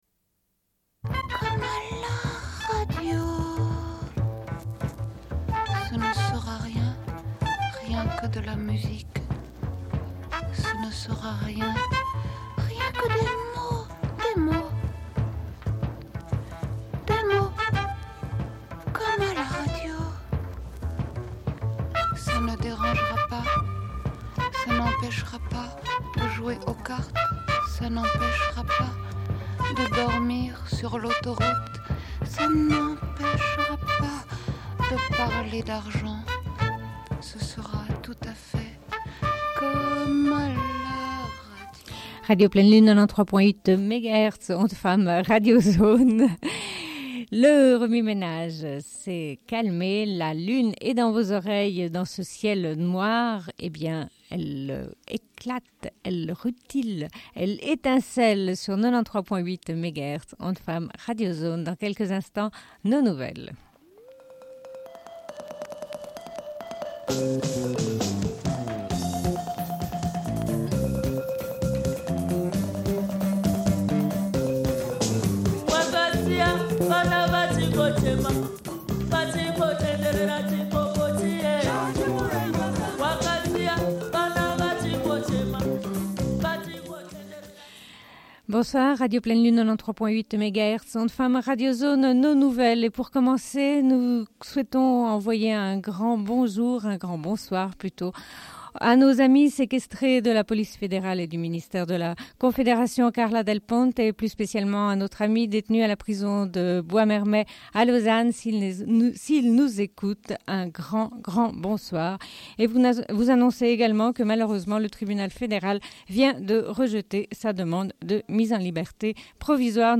Bulletin d'information de Radio Pleine Lune du 05.10.1994 - Archives contestataires
Une cassette audio, face B31:11